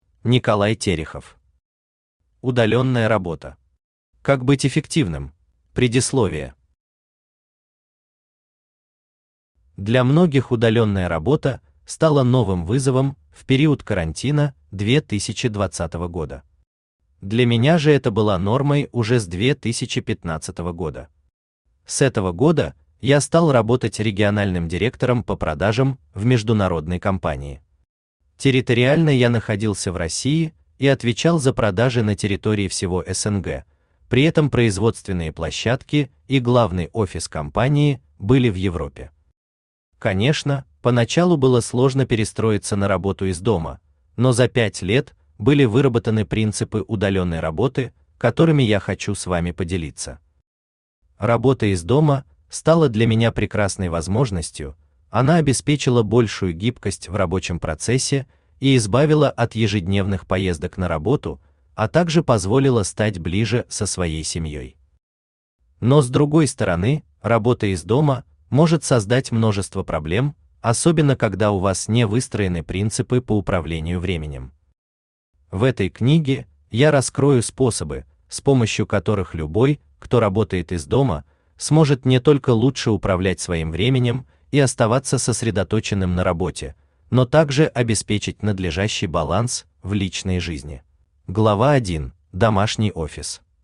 Аудиокнига Удалённая работа. Как быть эффективным?
Aудиокнига Удалённая работа. Как быть эффективным? Автор Николай Николаевич Терехов Читает аудиокнигу Авточтец ЛитРес.